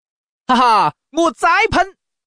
Index of /hunan_master/update/12815/res/sfx/changsha_man/